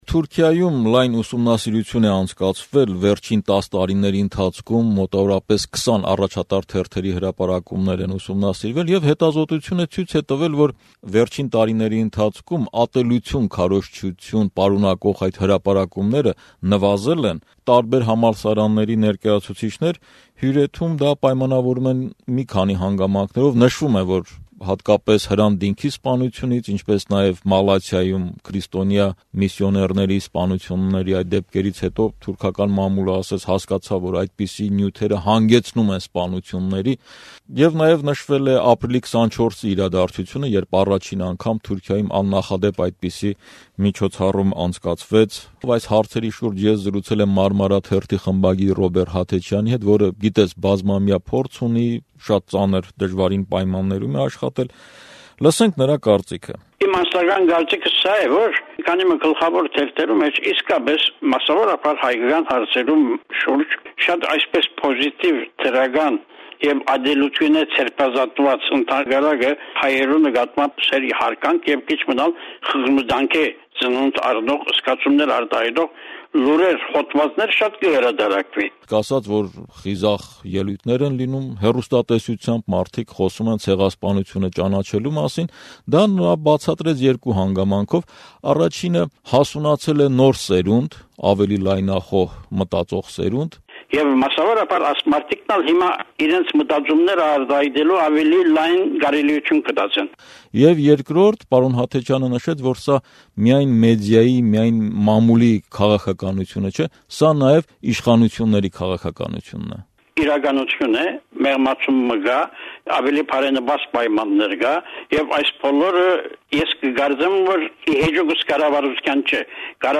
հարցազրույցը